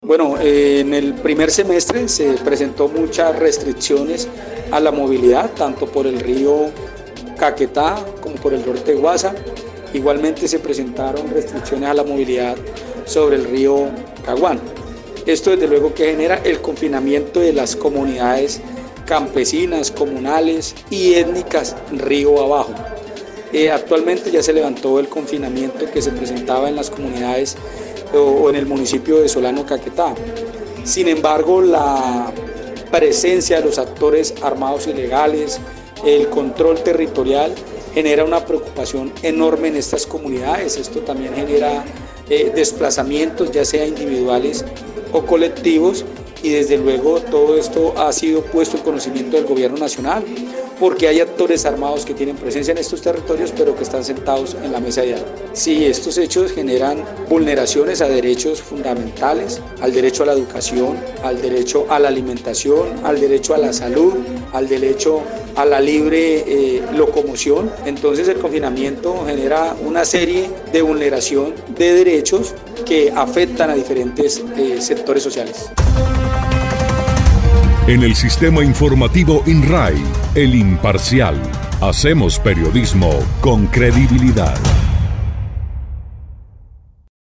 Edwin Leal, defensor del pueblo en Caquetá, explicó que, aunque el confinamiento generado en estas comunidades a principios del año, los grupos armados ilegales siguen teniendo presencia en la región, preocupa a las mismas comunidades.
03_DEFENSOR_EDWIN_LEAL_CONFINAMIENTO.mp3